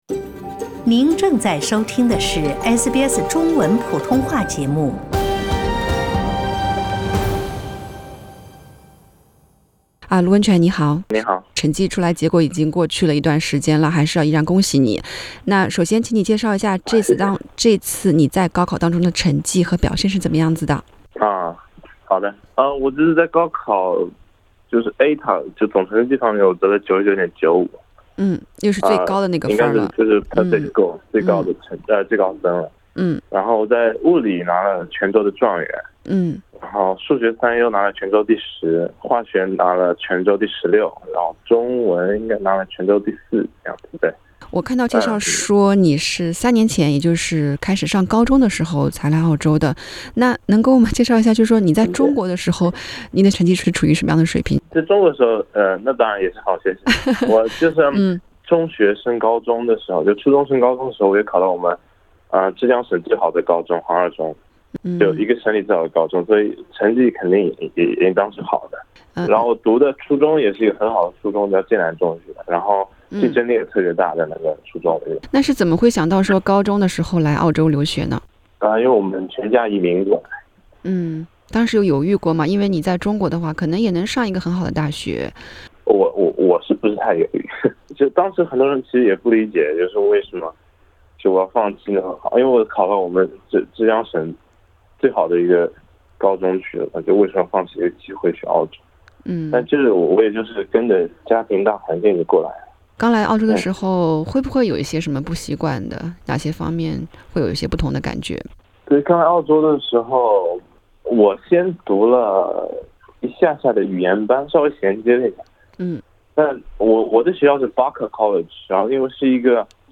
去年的HSC后，SBS普通话节目记者曾采访多位背景、经历截然不同的华裔学生，请他们讲述自己的“高考”故事